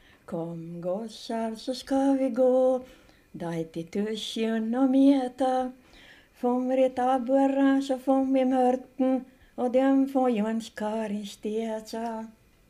Vispolska, fiolmelodi och hornlåt